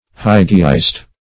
hygieist - definition of hygieist - synonyms, pronunciation, spelling from Free Dictionary Search Result for " hygieist" : The Collaborative International Dictionary of English v.0.48: Hygieist \Hy"gie*ist\, n. A hygienist.